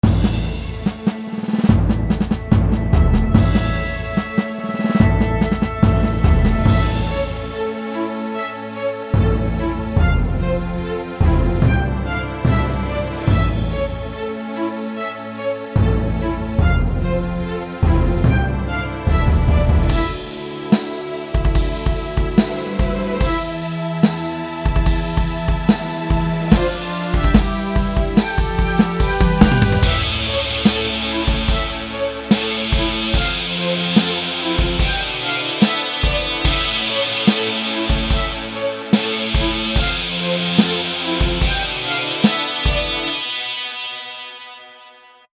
Apologies for the low quality, I had to render in mp3 format to fit the file size limit.
This definitely sounds more lighthearted, so I’m assuming the game it goes with will be the same. It also sounds a bit slower and less intense than what I would expect from a fighting game.